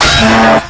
Tear02.wav